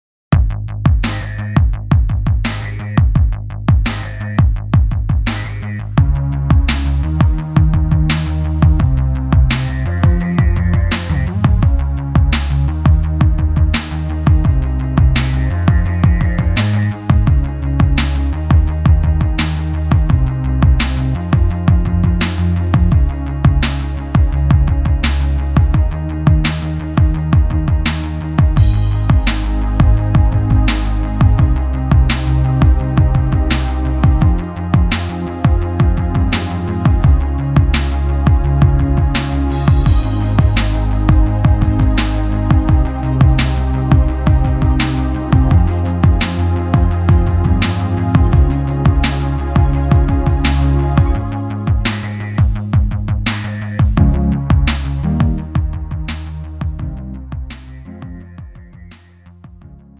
음정 -1키 2:59
장르 pop 구분 Pro MR